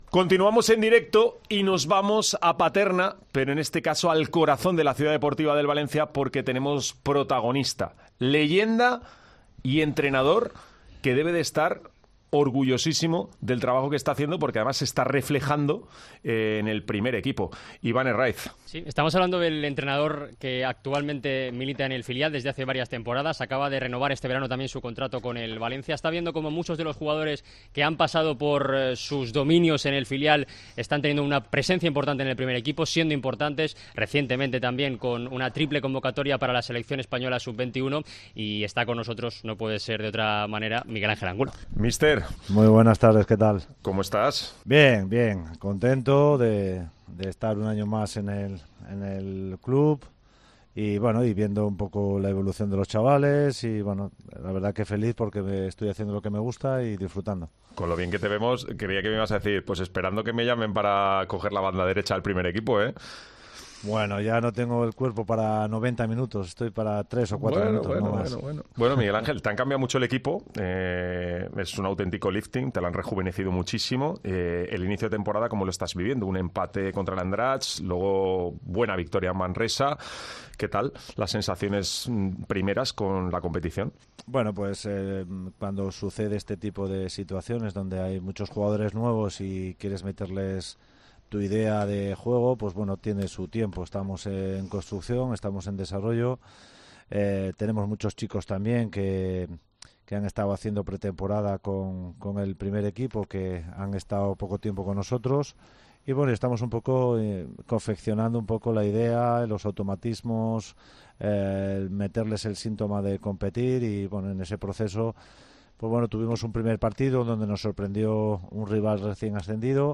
Atiende a Deportes COPE Valencia desde la Ciudad Deportiva de Paterna.